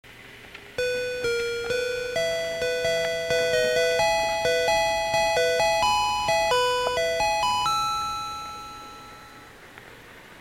肉声放送で流れるチャイムでこの車両でしか聴けないチャイムです。
チャイム１
ul-chime.mp3